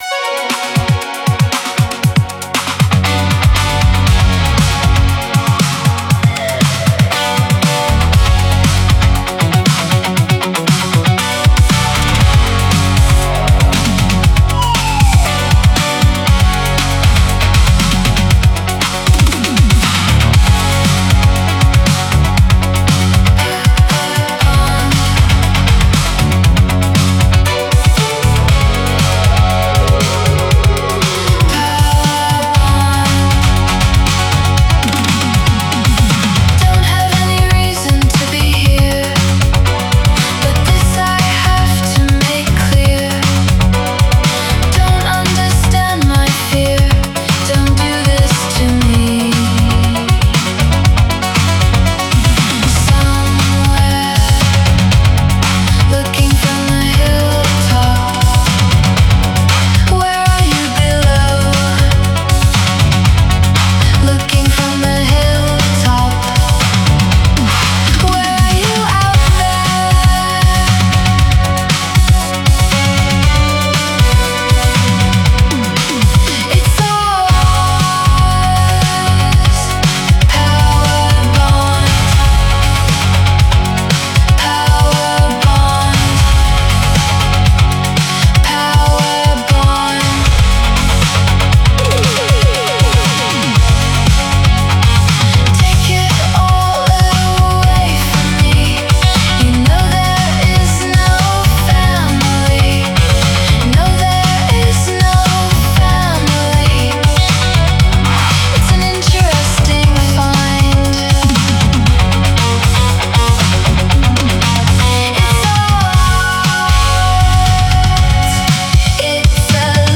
Genre Early Music